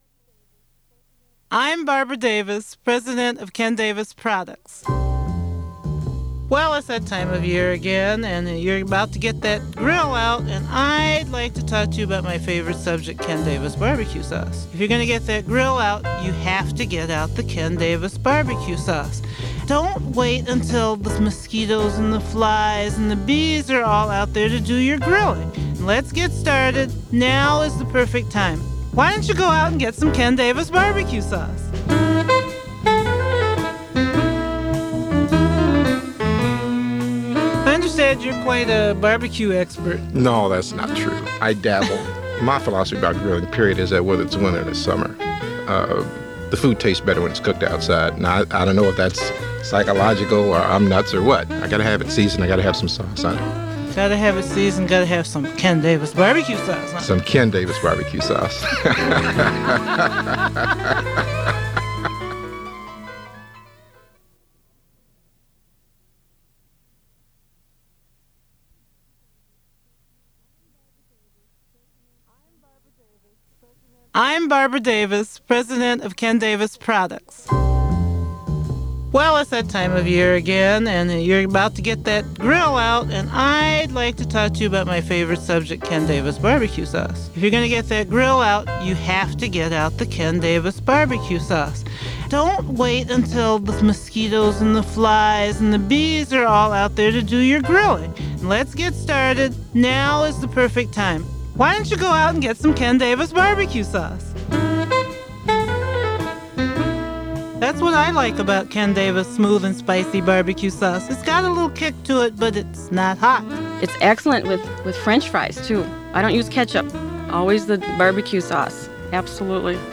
Advertising spots, undated, 1996-2000.
Cook House Recording Studios.